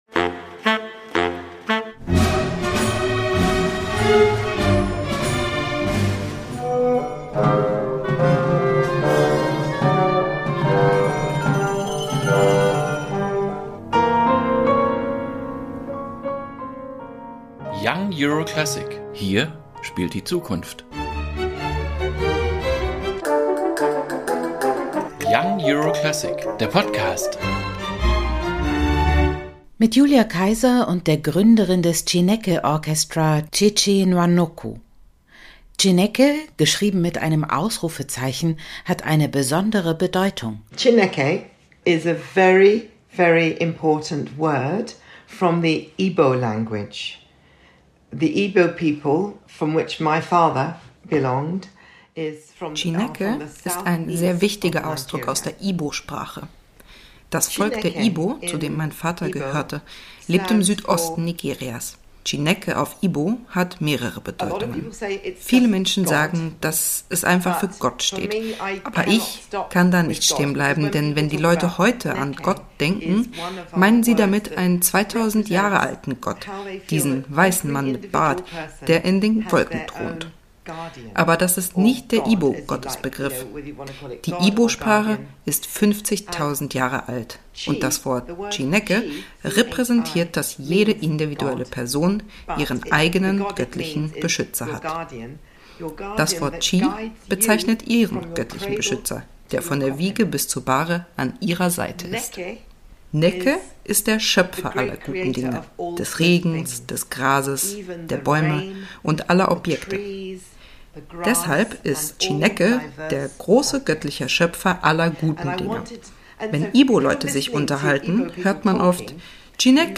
Warum Dazugehören nie selbstverständlich sein wird für die Musiker:innen dieser Orchester und inwiefern das auch Nwanokus Leben beeinflusst; wie sich die Verantwortung für die Zukunft der Klassik im Spiel der Chineke! Klangkörper widerspiegelt und warum alle britischen Kompositionsschaffenden Samuel Coleridge-Taylor dankbar sein müssen, das und mehr hat sie uns in dieser Episode erzählt. Das Gespräch